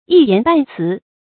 一言半辭 注音： ㄧ ㄧㄢˊ ㄅㄢˋ ㄘㄧˊ 讀音讀法： 意思解釋： 見「一言半語」。